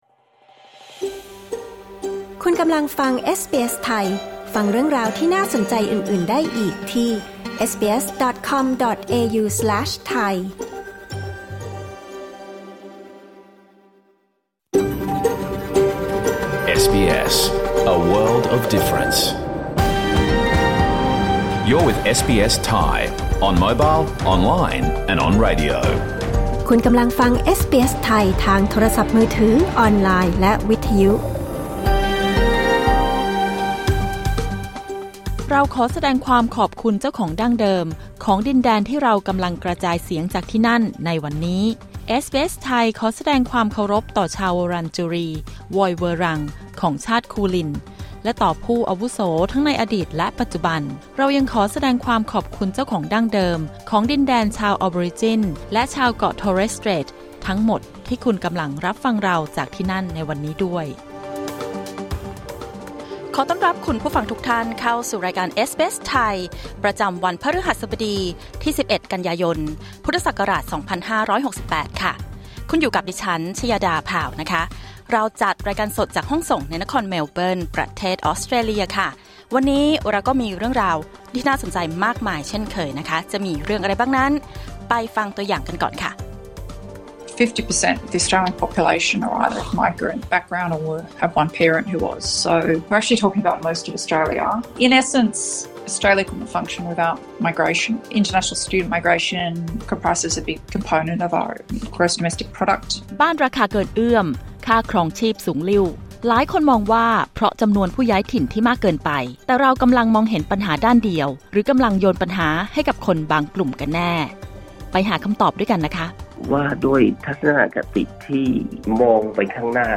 รายการสด 11 กันยายน 2568